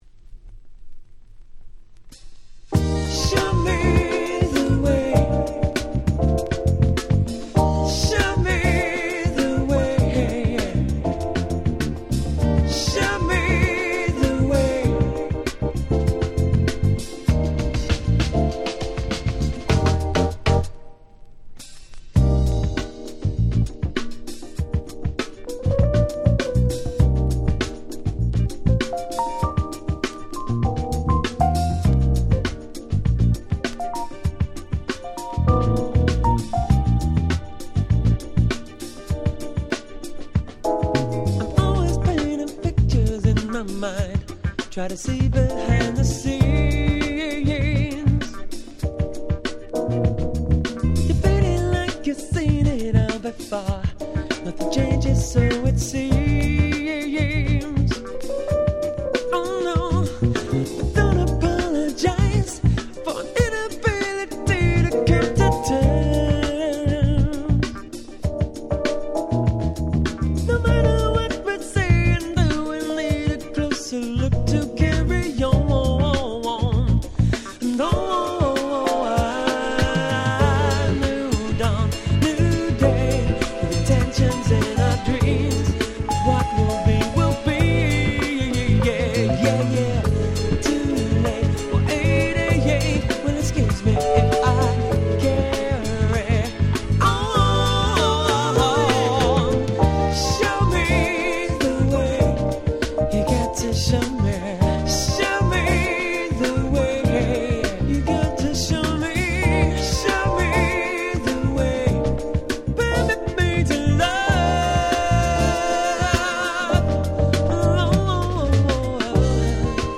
95' Very Nice UK Soull / Acid Jazz !!
全編爽やかですがどこか土の香りがするEarthyなUK Soulナンバーがてんこ盛り。